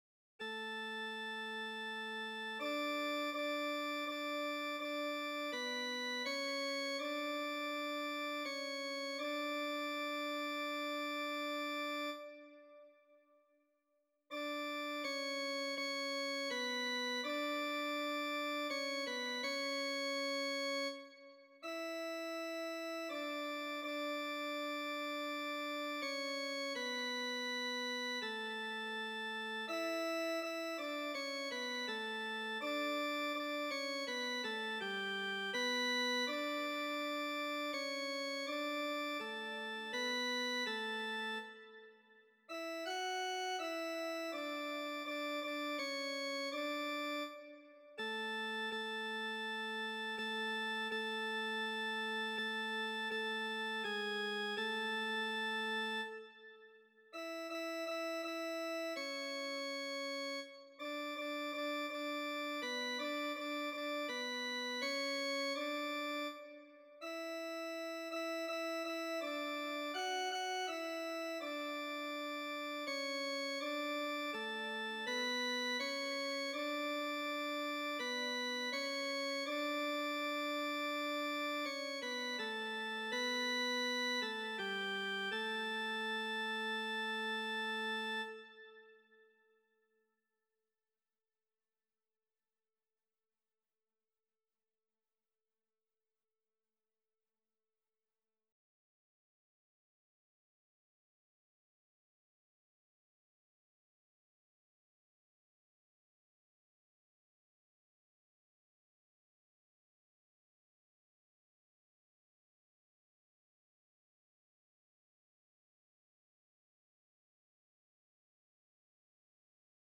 Répétition SATB4 par voix
Ténor     (ténor)
Le décor "O Magnum Mysterium" de Dom Pedro de Christo est une œuvre chorale en quatre parties qui se caractérise par ses harmonies riches et ses mélodies expressives.